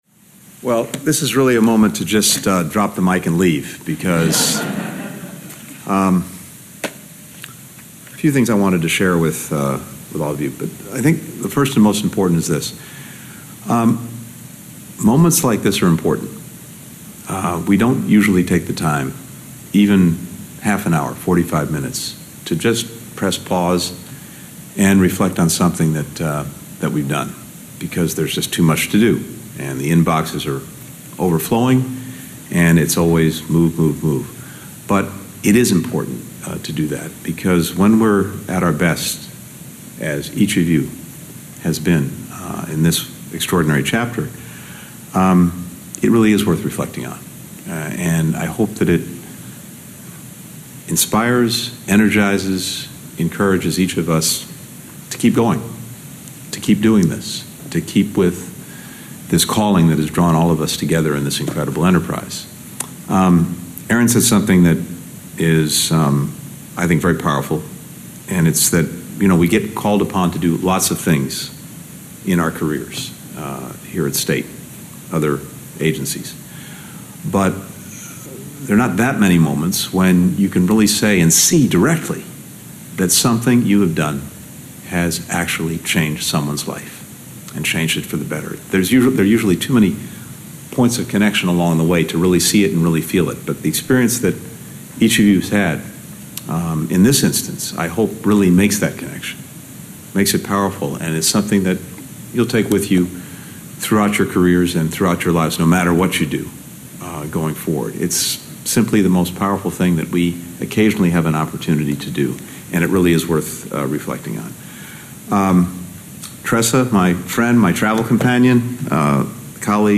Antony J. Blinken - Remarks at State Dept Event Honoring Workforce Serving 222 Released Nicaragua Political Prisonsers (transcript-audio-video)